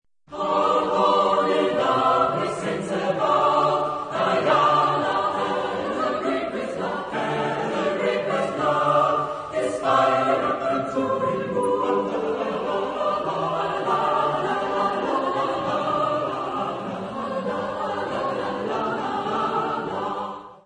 Epoque: 17th century
Genre-Style-Form: Madrigal
Type of Choir: SSATB  (5 mixed voices )
Tonality: mixolydian
Discographic ref. : 3.Deutscher Chorwettbewerb, 1990